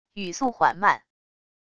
语速缓慢wav音频